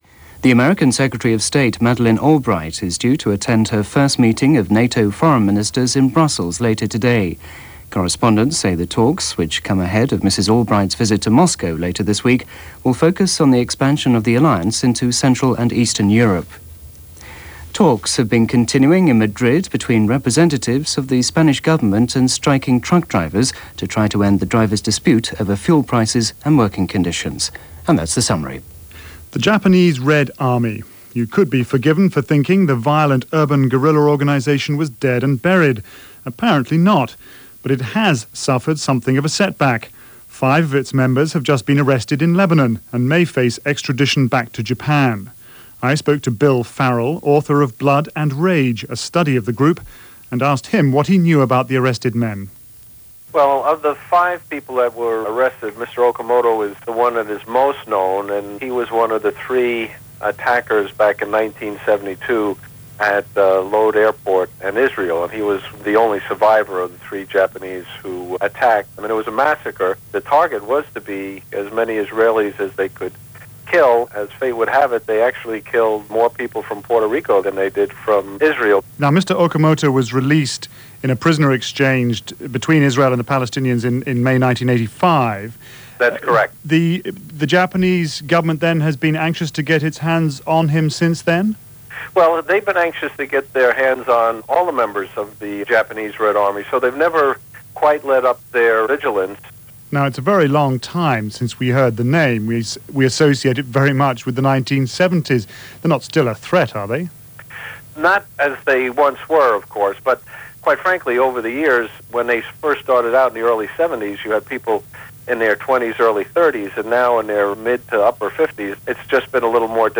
February 18, 1996 – BBC World Service News – Gordon Skene Sound Collection –